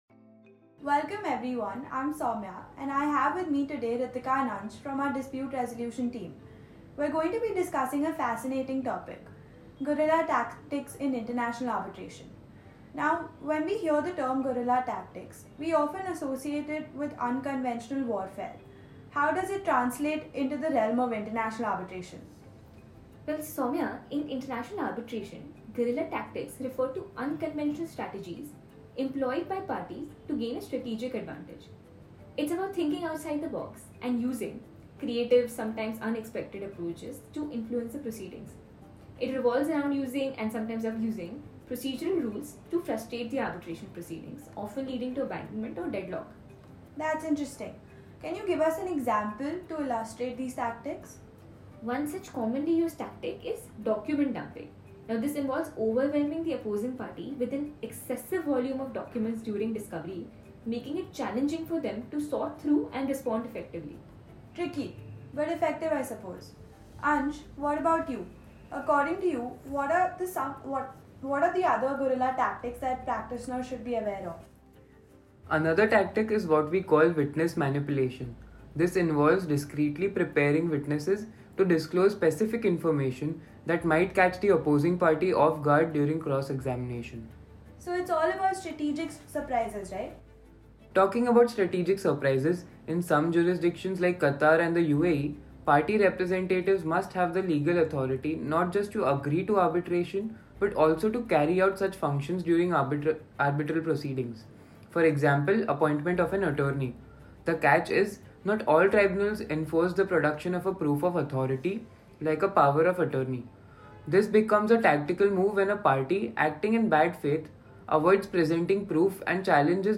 In this podcast, members of our international dispute resolution and investigations team